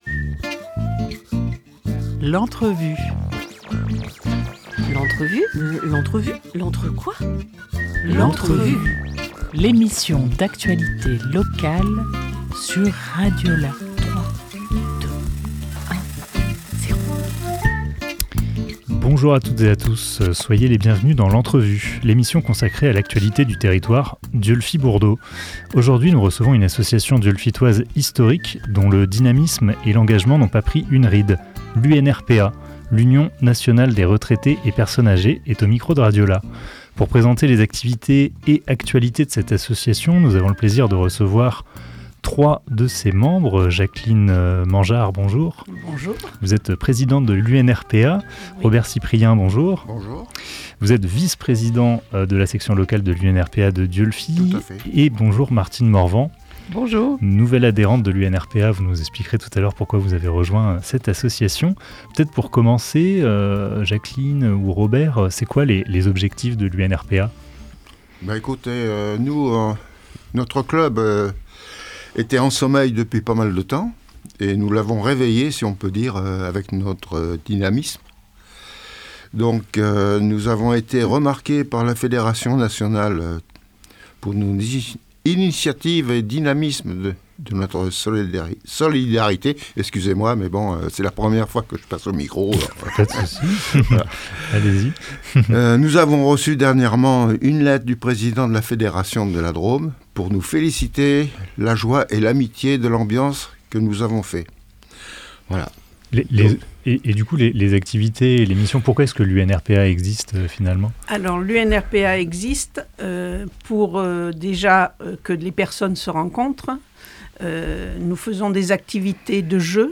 31 octobre 2024 11:24 | Interview